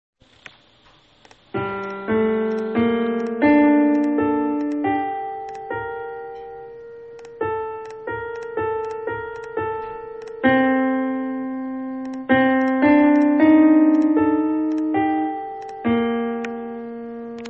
Наиграл одним пальцем :)).